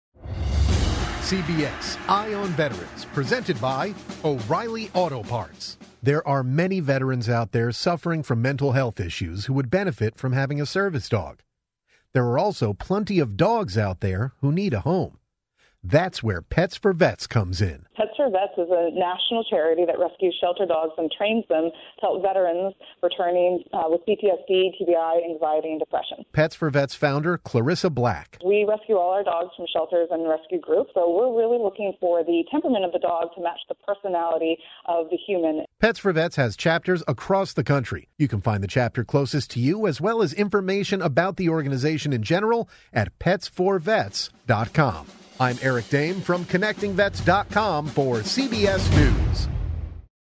Each daily one-minute news update provides military veterans and their families with information that impacts their lives: